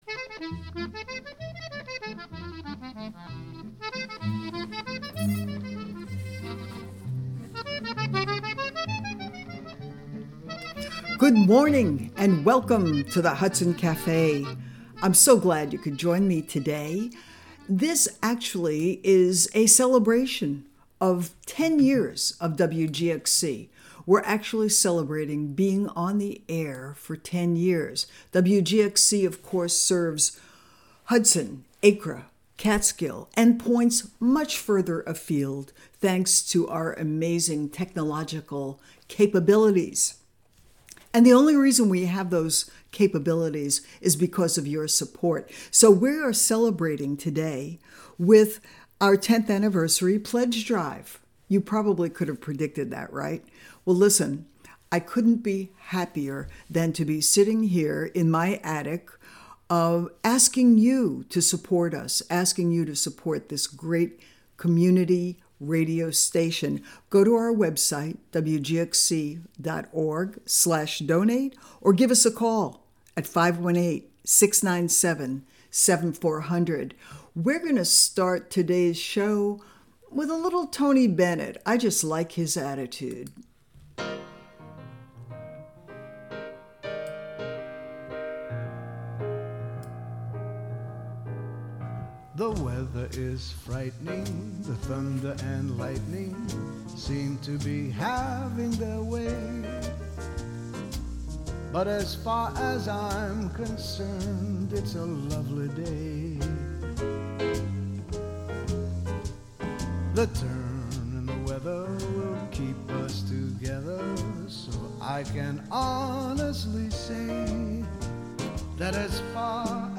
A special pledge drive broadcast.
The Hudson Café is an upbeat music show featuring the American songbook, as interpreted by contemporary artists as well as the jazz greats in a diverse range of genres.